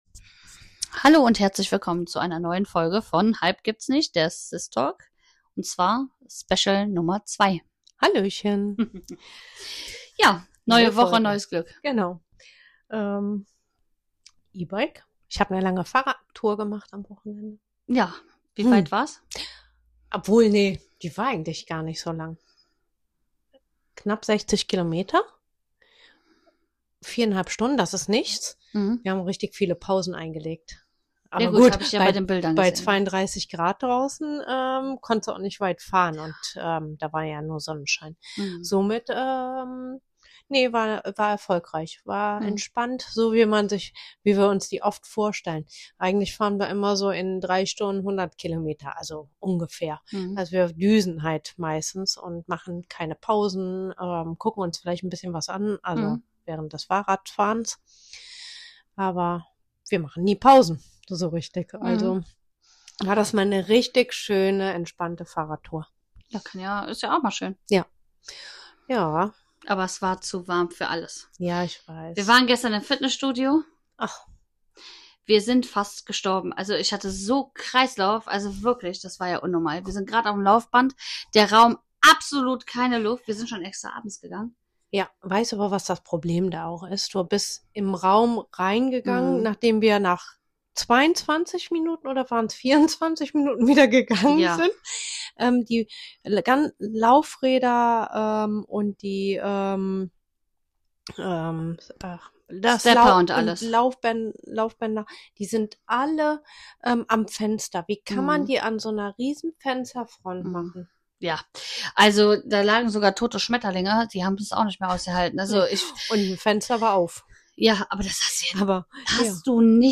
Die zwei Schwestern sprechen ehrlich über Schreckmomente, Erste-Hilfe-Gefühle und das schlechte Gewissen, das sich manchmal dazumischt. Dazu: Sport – Fluch oder Segen?
Ehrlich, direkt und mit einem Augenzwinkern – wie immer.